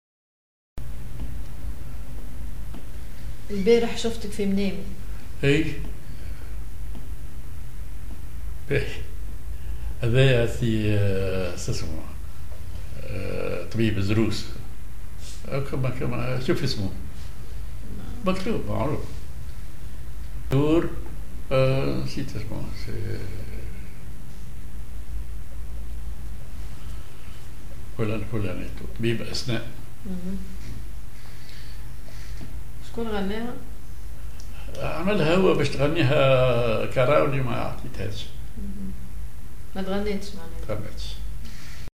Maqam ar أصبعين
genre أغنية